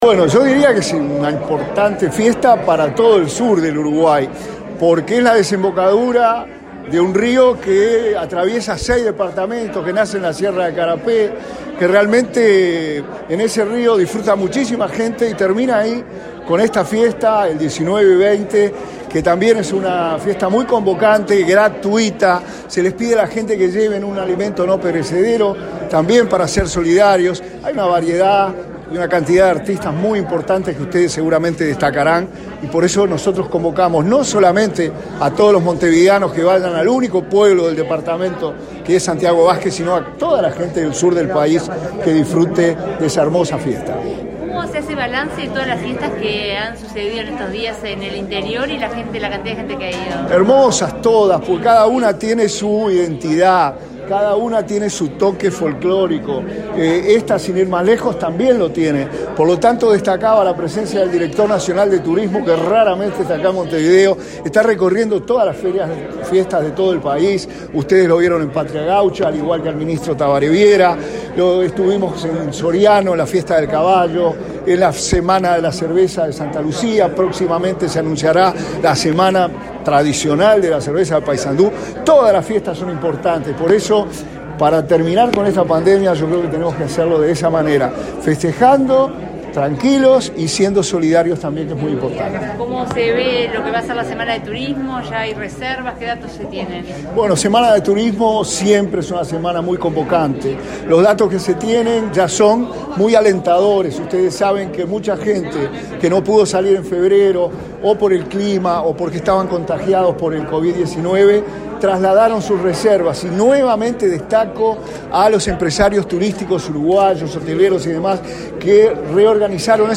Declaraciones a la prensa del subsecretario de Turismo, Remo Monzeglio
Este lunes 14, en Montevideo, el subsecretario de Turismo, Remo Monzeglio, participó en el lanzamiento de la Fiesta del Río, que se realizará los días